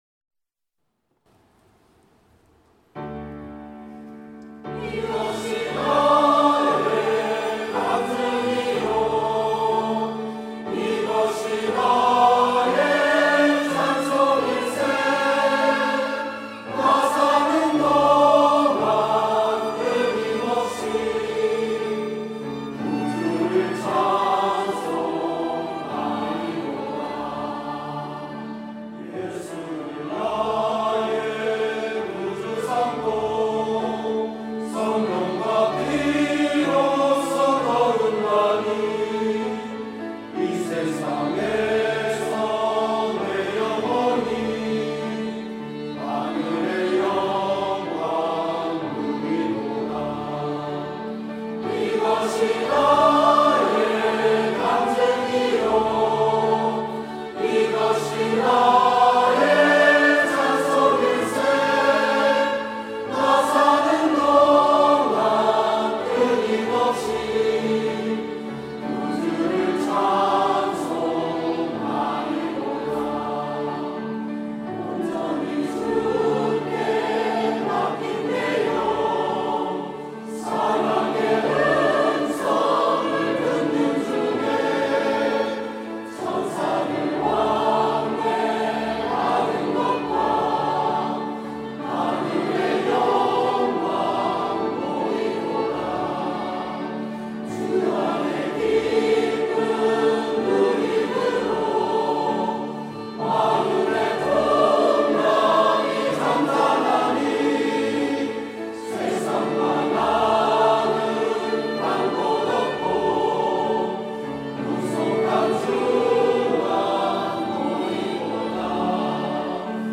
1부 찬양대